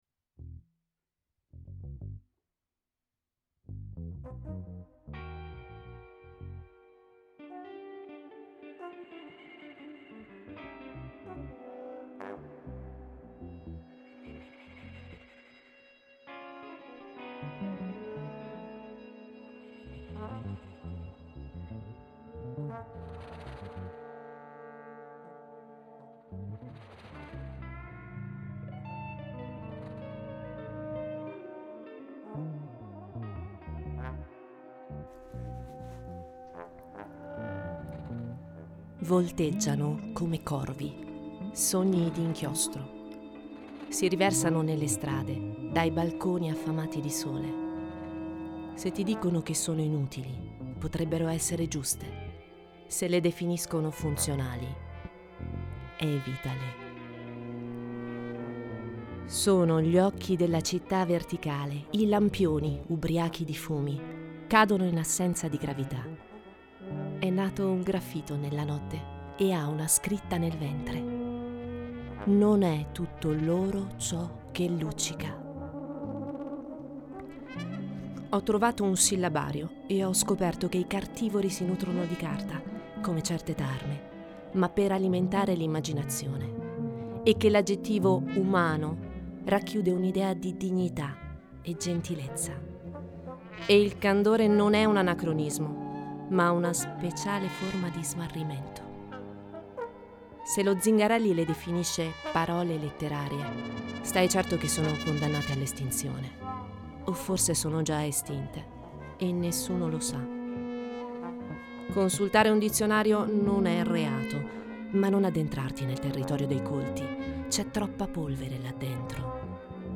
Audio libri